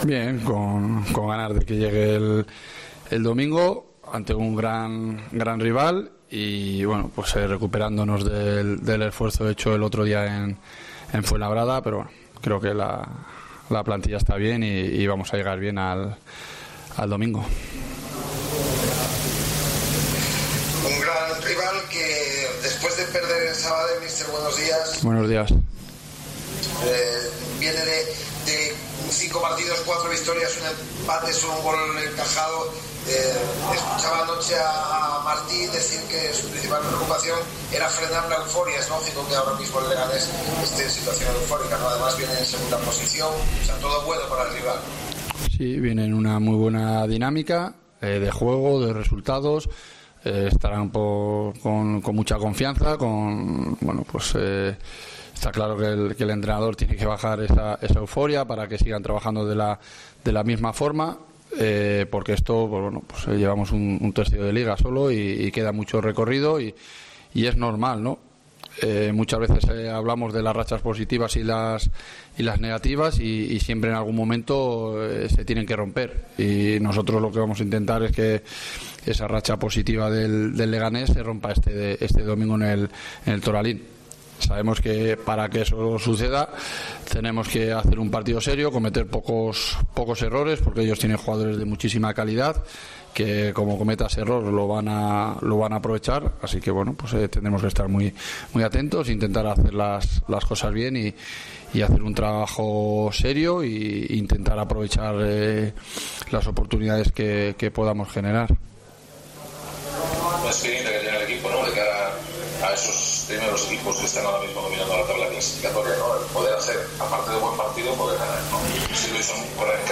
AUDIO: Escucha aquí las palabras del entrenador de la Ponferradina